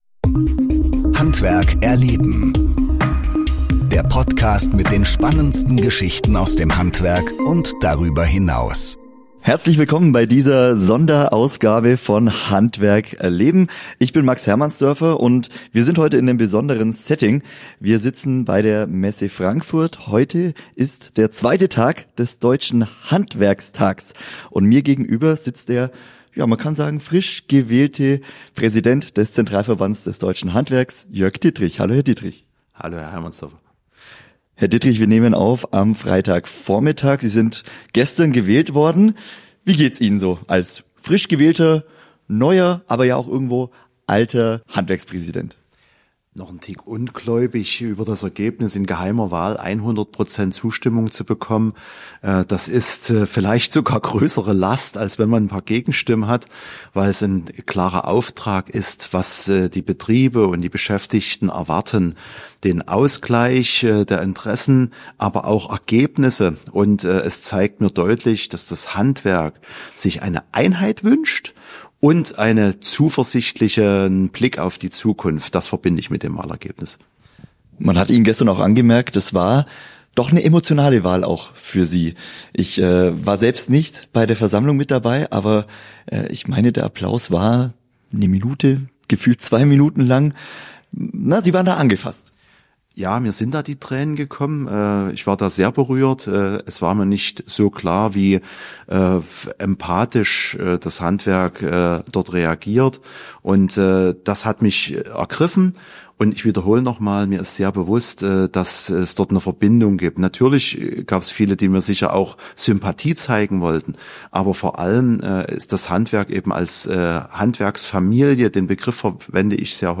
mit dem frisch wiedergewählten ZDH-Präsidenten Jörg Dittrich